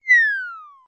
default_death2.mp3